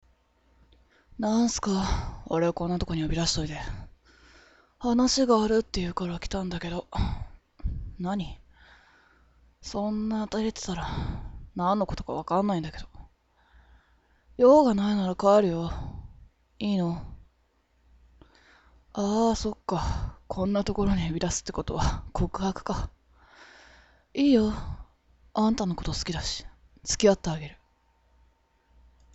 (R18に関しましてもご相談により可能な場合もございます) 癒し系の声に好評頂いておりますがキャラに合わせて声を変えさせていただきますのでご依頼の際にはキャラの設定等わかるものを添えていただけると非常に助かります。